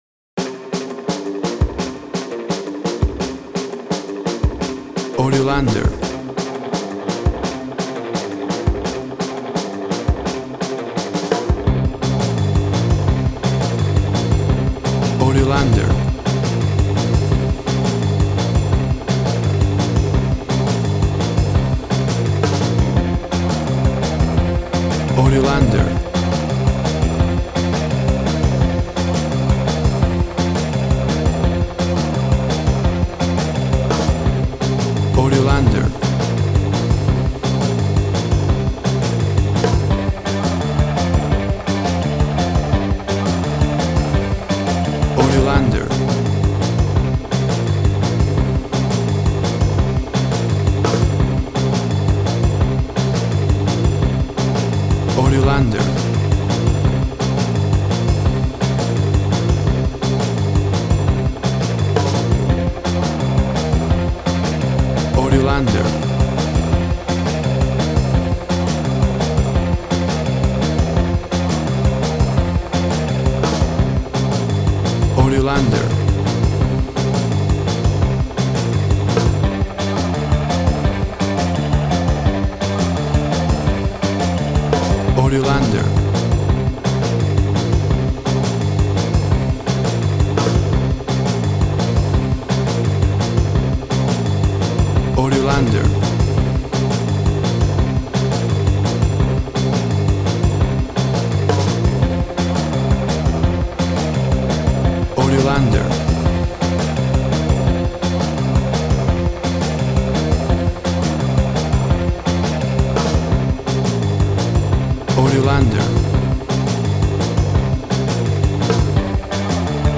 WAV Sample Rate 16-Bit Stereo, 44.1 kHz
Tempo (BPM) 172